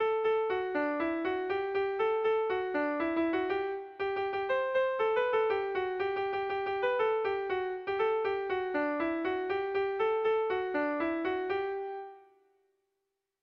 Air de bertsos - Voir fiche   Pour savoir plus sur cette section
Irrizkoa
ABDE